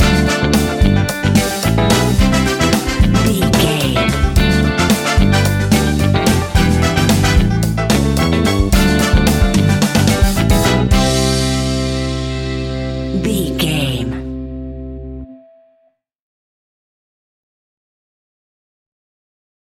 Ionian/Major
flamenco
latin
salsa
uptempo
drums
bass guitar
percussion
brass
saxophone
trumpet
fender rhodes
clavinet